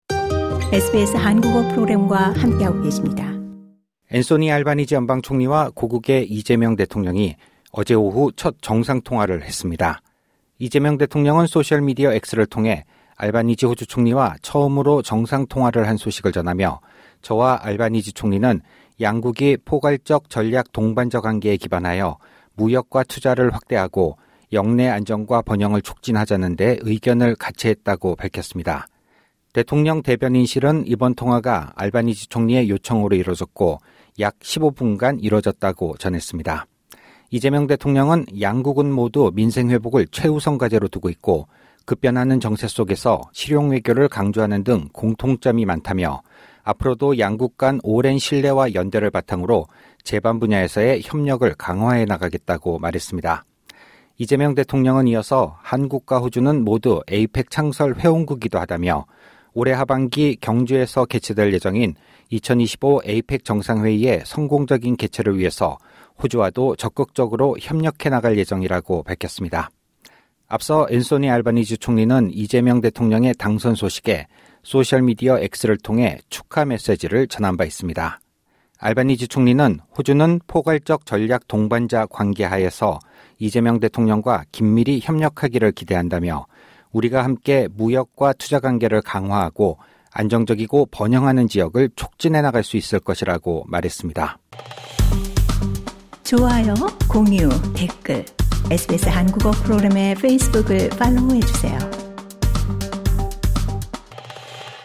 SBS Korean 15:25 Korean 상단의 오디오를 재생하시면 뉴스를 들으실 수 있습니다.